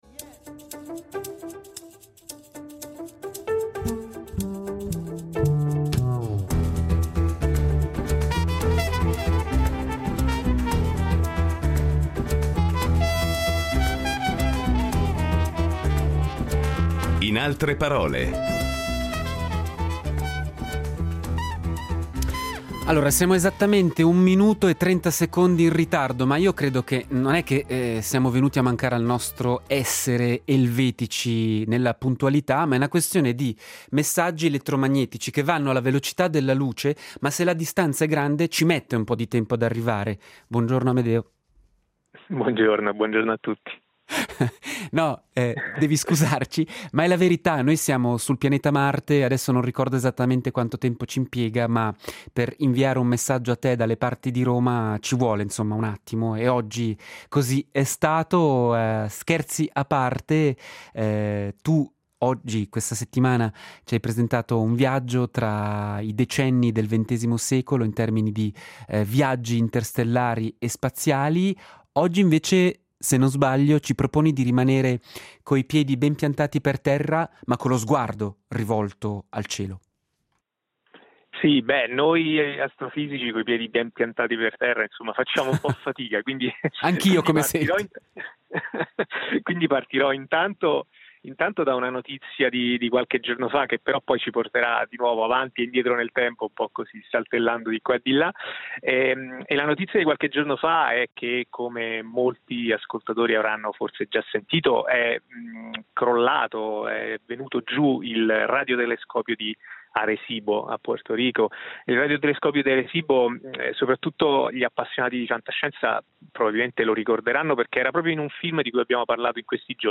Nella settimana che vedrà svolgersi la European Space Week, “In altre parole” ha pensato di chiedere ad un astrofisico e divulgatore scientifico di accompagnare gli ascoltatori nella consueta rassegna di conversazioni del mattino.